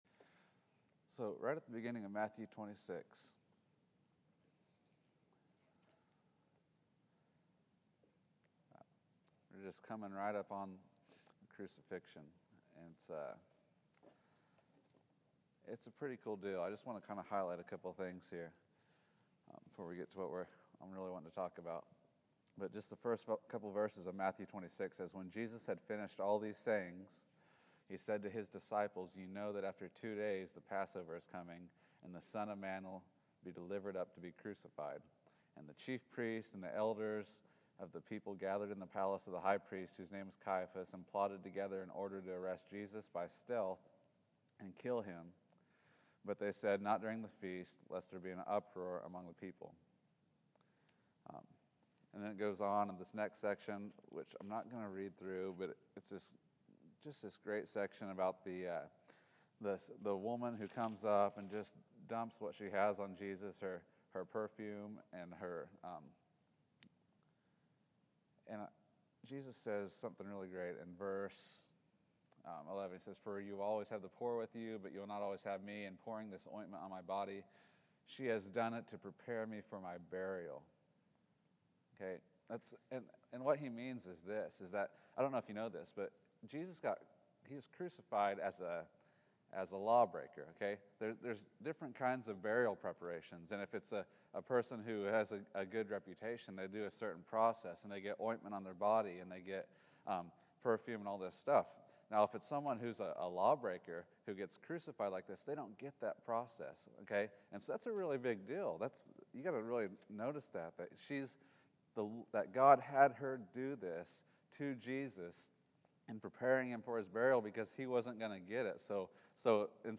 Matthew 26 March 30, 2014 Category: Sunday School | Location: El Dorado Back to the Resource Library The centrality of the Cross.